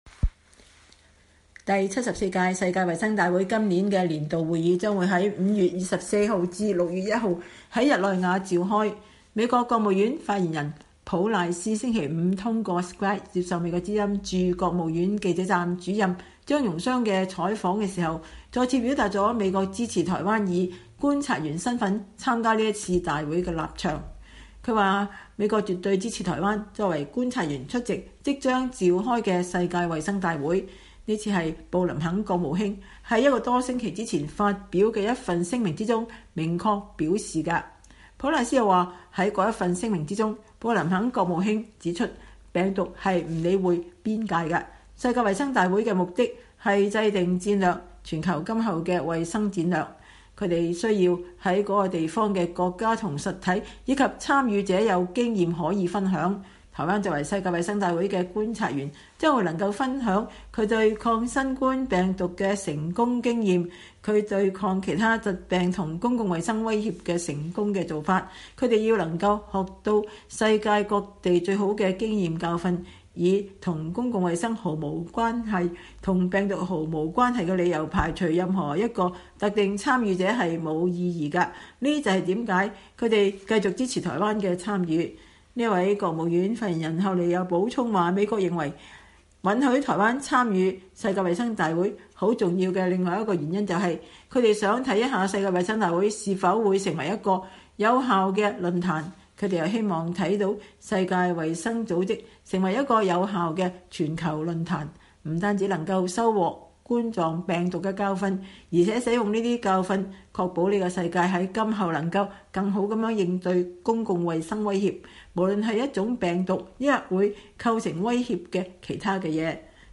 美國國務院發言人普賴斯通過Skype接受美國之音的專訪。(2021年5月14日)
美國國務院發言人普賴斯星期五通過Skype接受美國之音採訪時再次表達了美國支持台灣以觀察員身份參加世衛大會的立場。